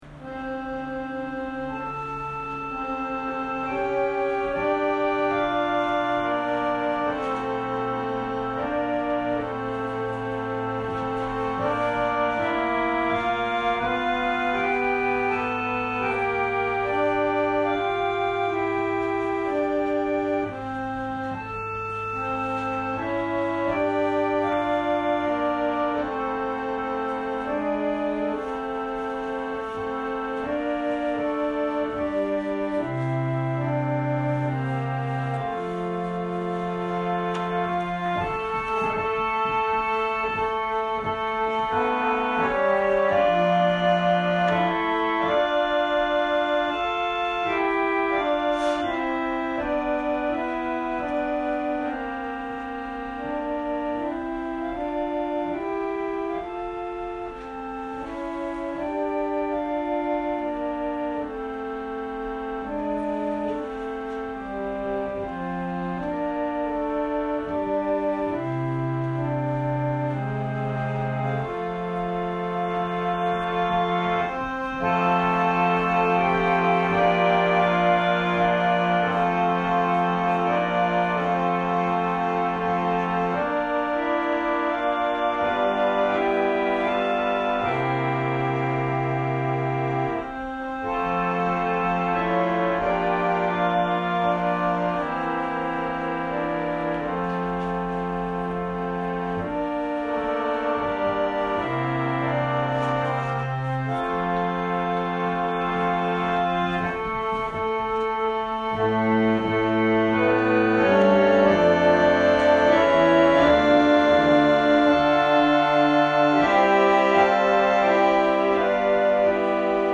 音声ファイル 礼拝説教を録音した音声ファイルを公開しています。